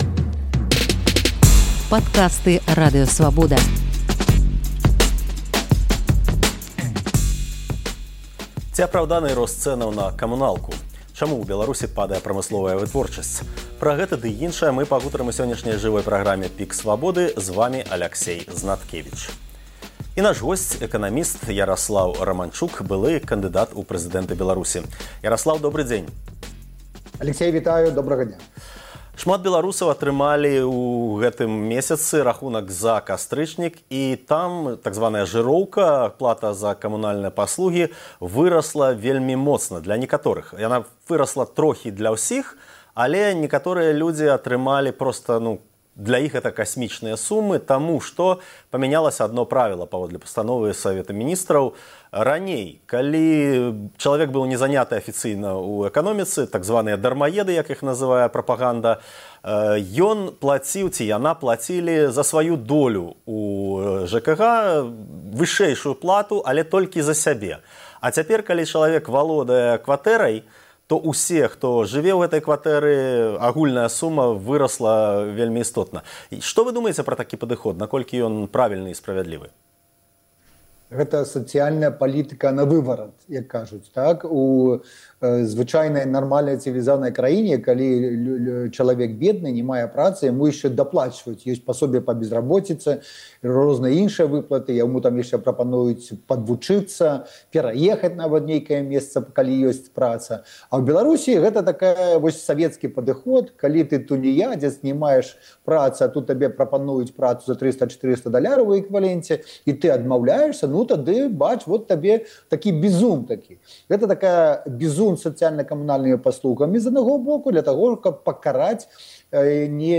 Ці апраўданы рост цэнаў на камунальныя паслугі? Чаму ў Беларусі падае вытворчасьць? Пра гэта ў жывым эфіры Свабоды разважае эканаміст Яраслаў Раманчук, былы кандыдат у прэзыдэнты.